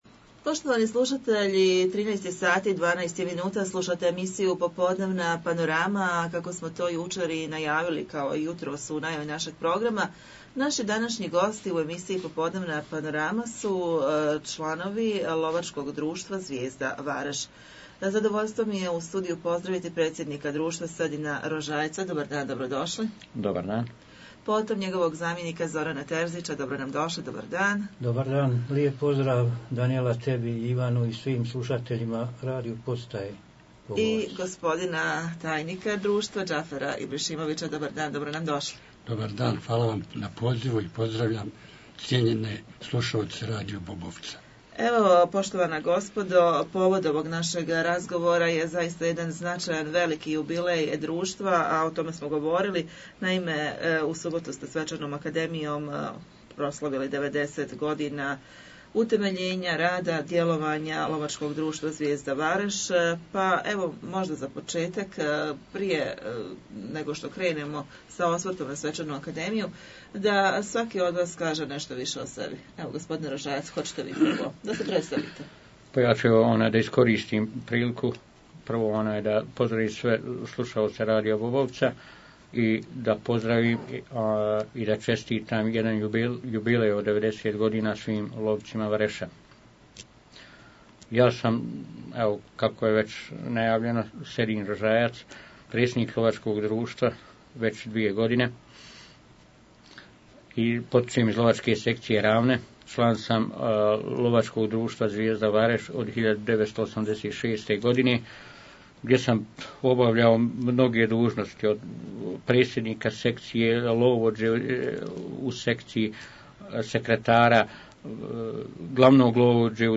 U povodu 90 godina rada Lovačkog društva Zvijezda, ugostili smo predstavnike ovog društva, pa poslušajte.....